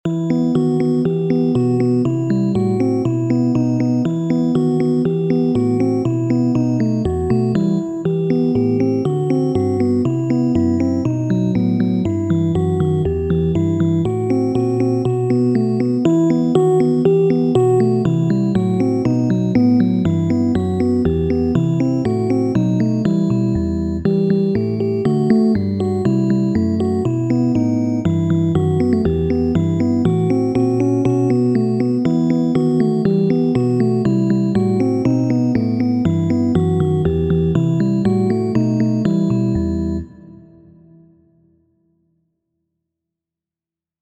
Klaku por aŭdi andanton de Fernando Sor.